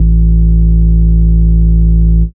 Sub Bass (JW2).wav